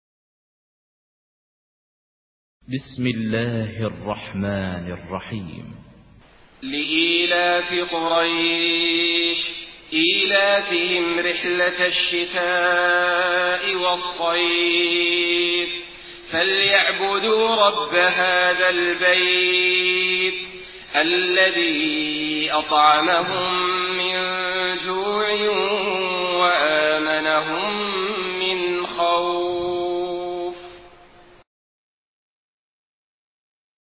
Surah Quraish MP3 in the Voice of Saleh Al-Talib in Hafs Narration
Surah Quraish MP3 by Saleh Al-Talib in Hafs An Asim narration.
Murattal